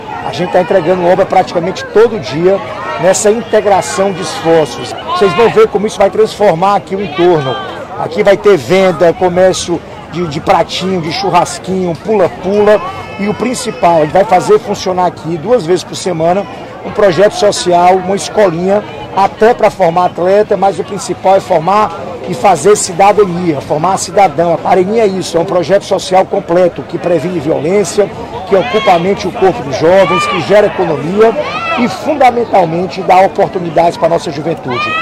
Para o prefeito de Fortaleza, Roberto Cláudio, as intervenções trazem uma série de benefícios para a população local.